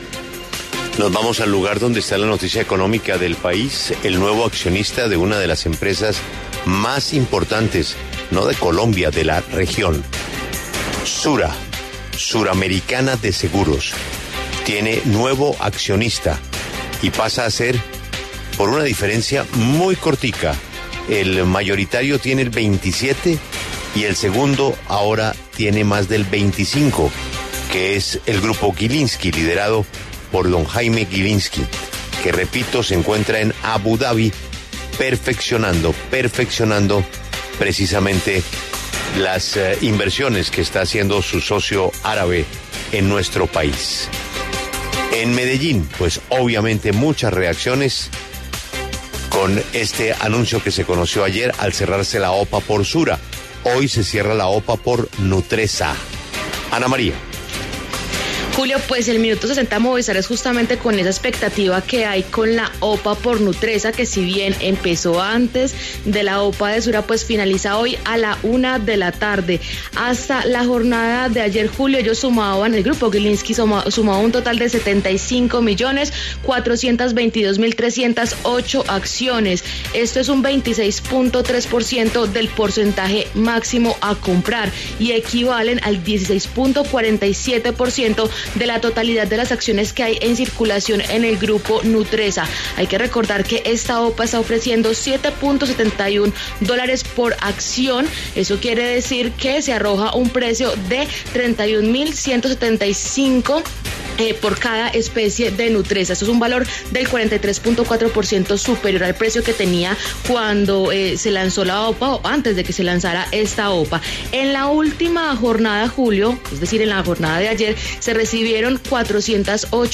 En diálogo con La W